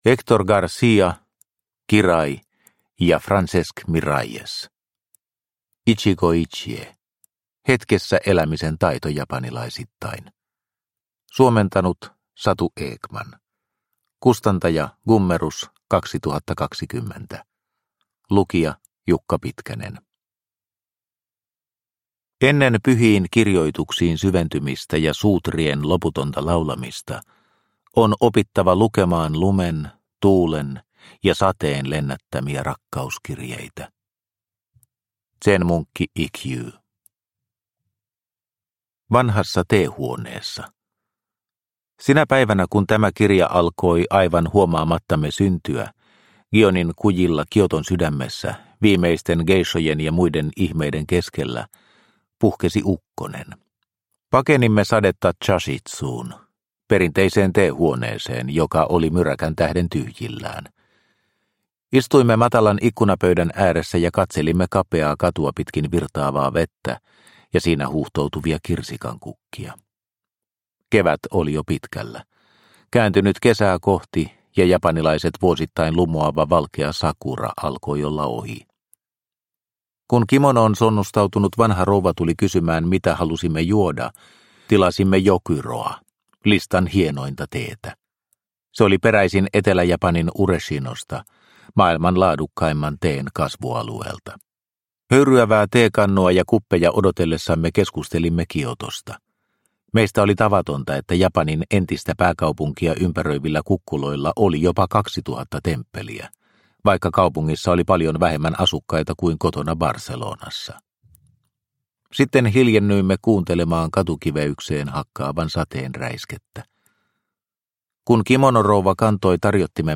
Ichigo ichie – Ljudbok – Laddas ner